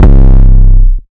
808 [ droid ].wav